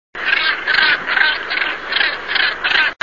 Głuptak - Morus bassanus
głosy